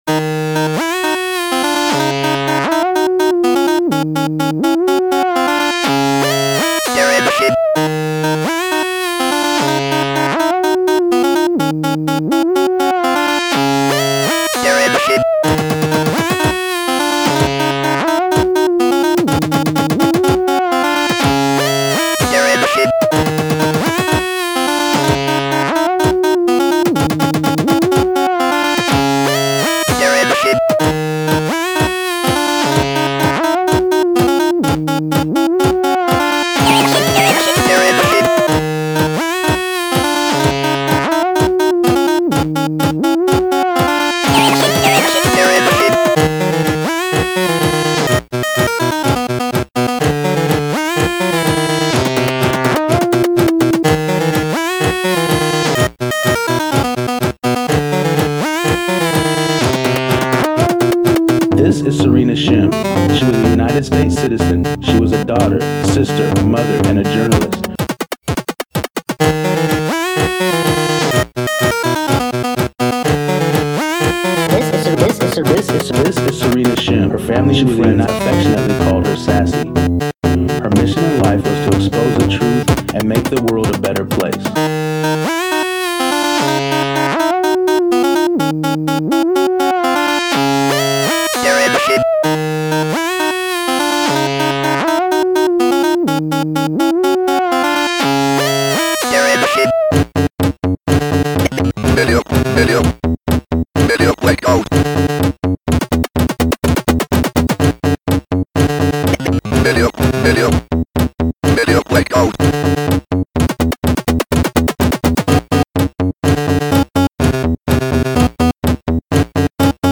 is a chiptune composed in Milky Tracker on the PC and mixed in Audacity. The tune features instruments sampled from the Acorn Electron and the Commodore 64 (midi piano keyboard with MSSIAH cartridge attached) as well as sampled speech from the C64.